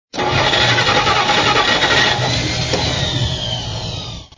Dzwonki Car Start
Kategorie Efekty Dźwiękowe